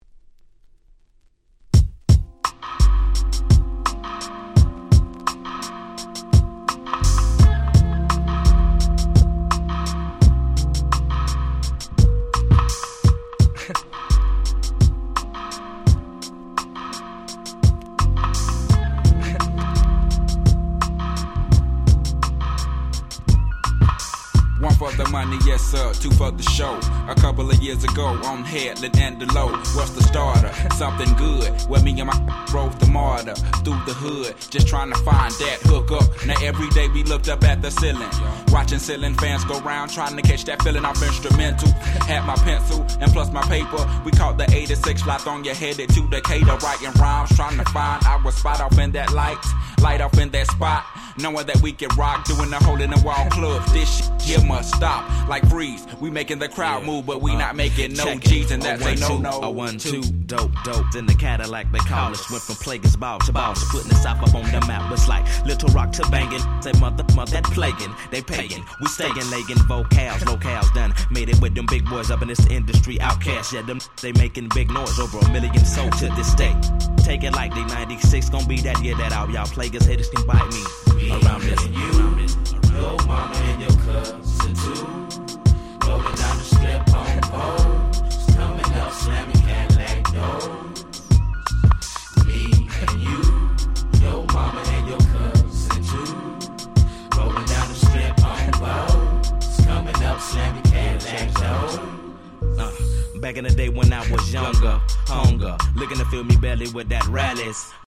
01' Monster Hit Hip Hop !!